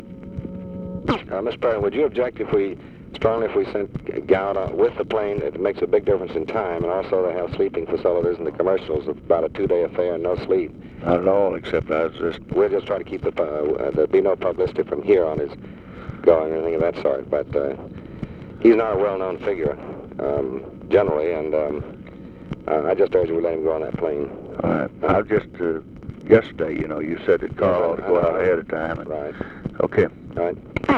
Conversation with DEAN RUSK, March 3, 1965
Secret White House Tapes